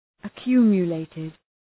Προφορά
{ə’kju:mjə,leıtıd}